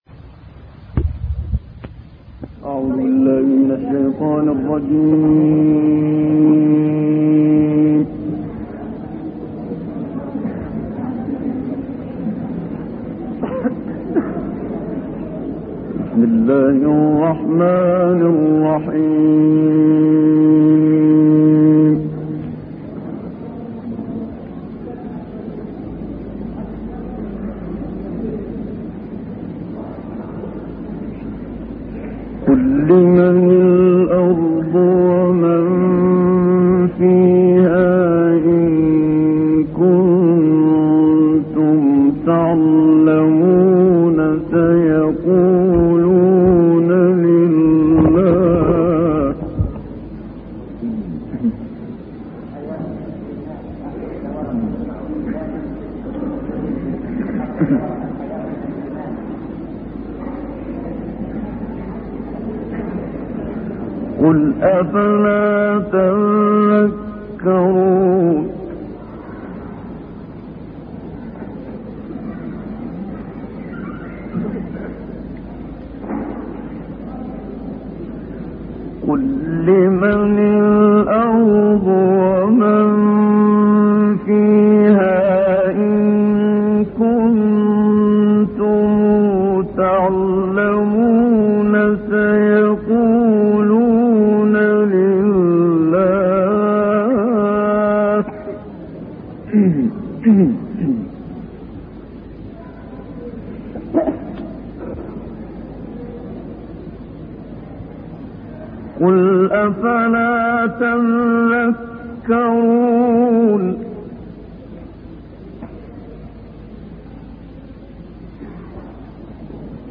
023 المؤمنون 84-116 تلاوات نادرة بصوت الشيخ محمد صديق المنشاوي - الشيخ أبو إسحاق الحويني